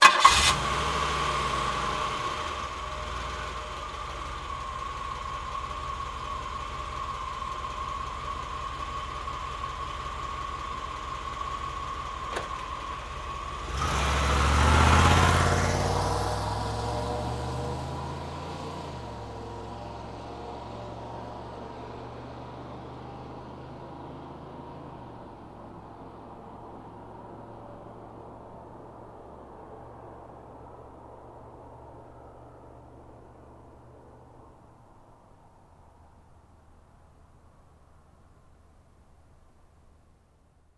Cadillac 1990 Exterior Start, Idle, Away Very Fast Speed